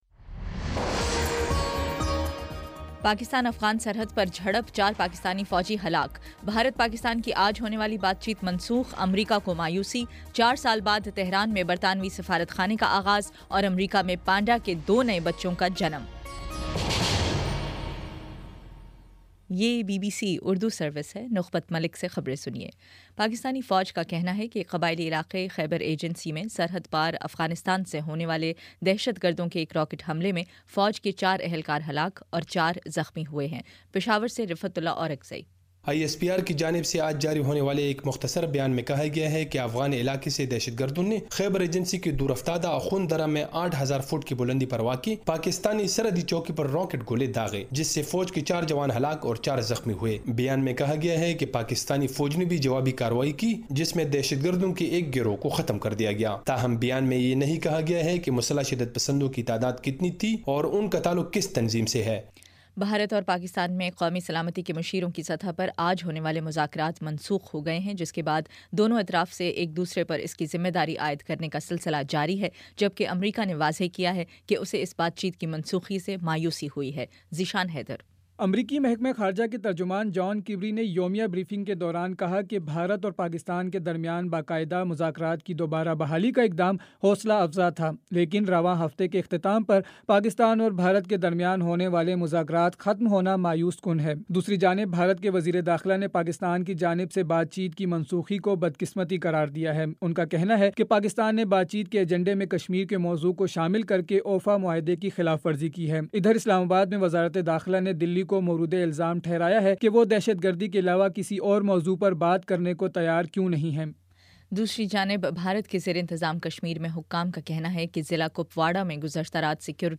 اگست 23: شام پانچ بجے کا نیوز بُلیٹن